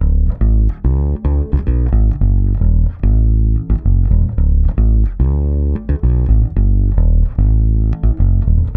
-AL AFRO D#.wav